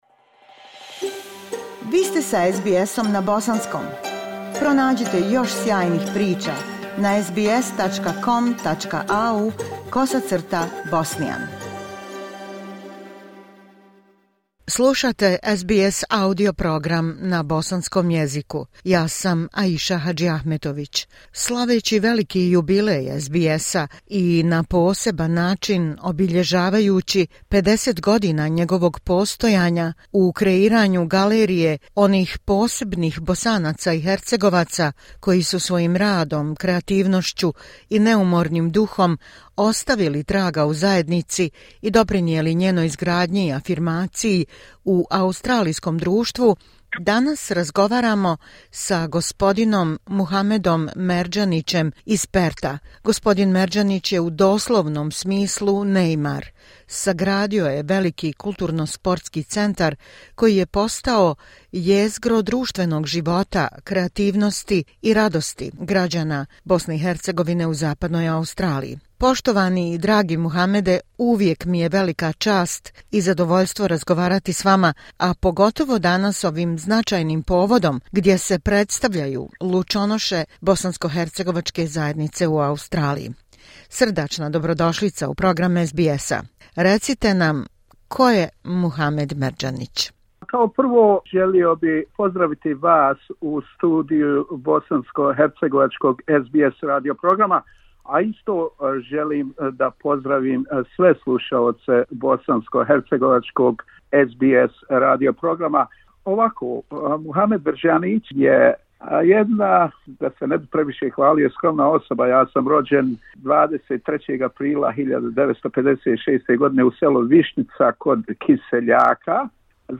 donosimo razgovor